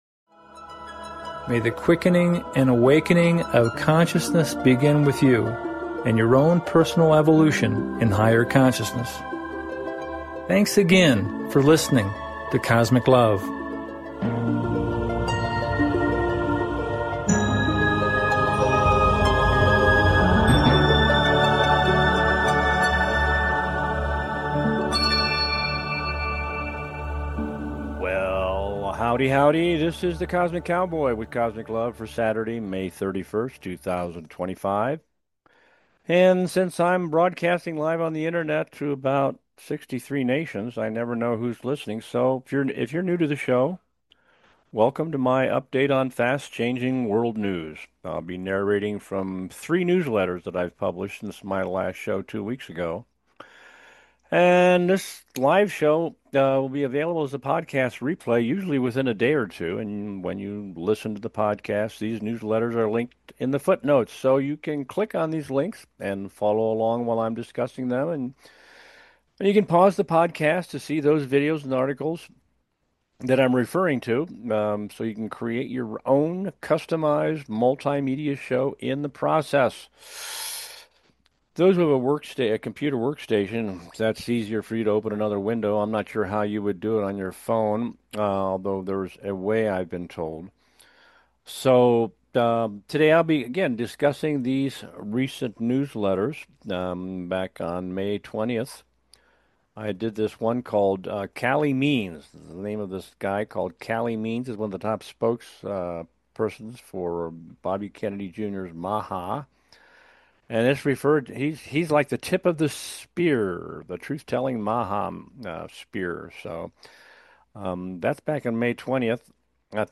Cosmic LOVE Talk Show